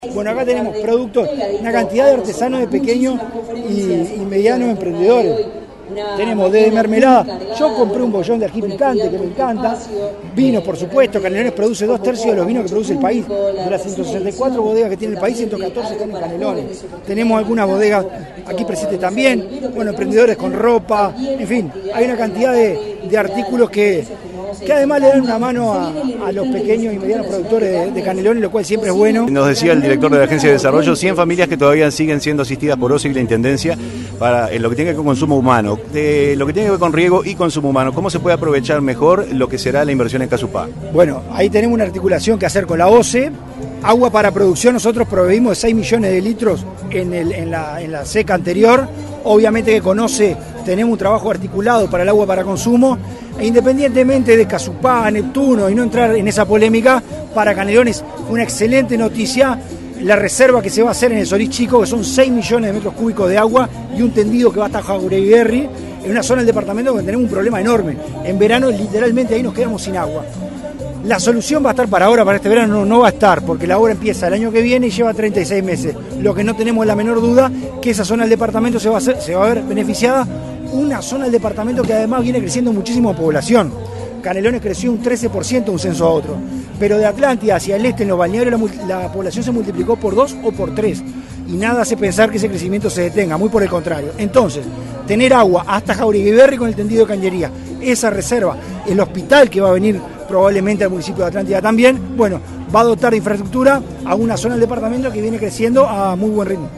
El intendente, Francisco Legnani, presente en la Expo Prado, habló luego de la inauguración.